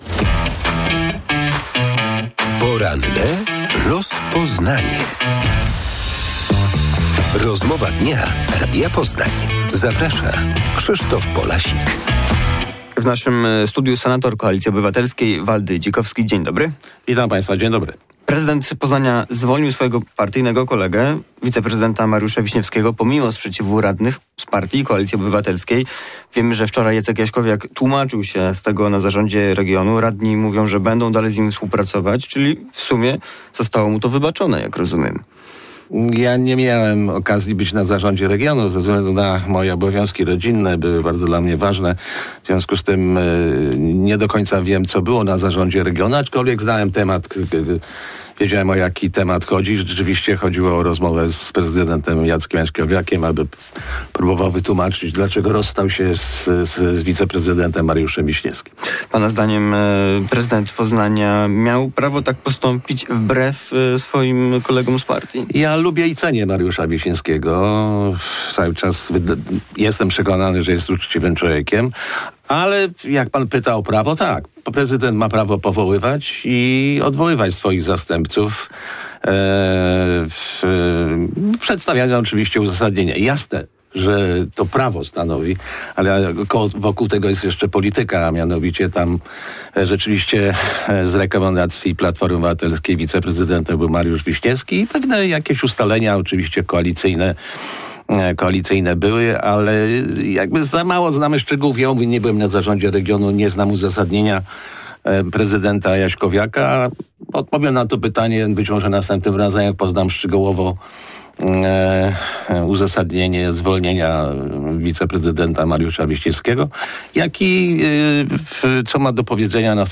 y9q339oyjqt8iqe_poranna_rozmowa_dzikowski.mp3